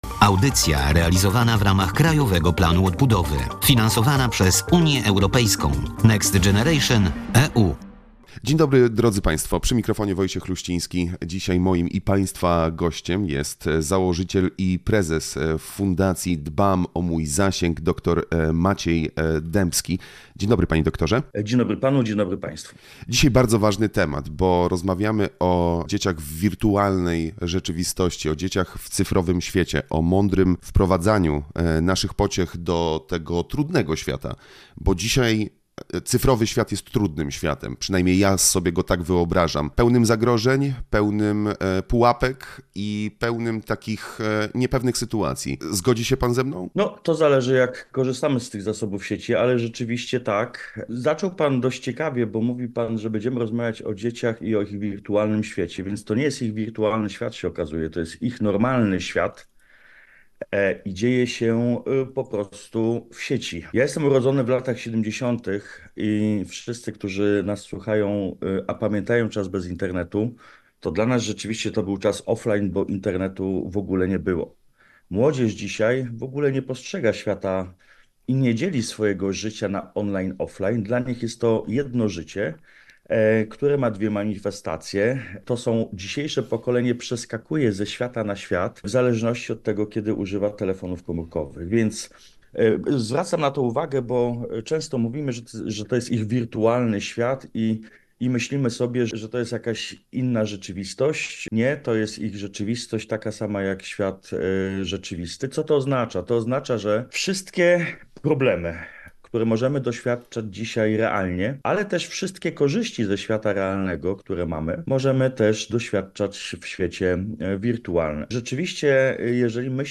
– Warto zachować ten balans – podkreślił prowadzący audycję.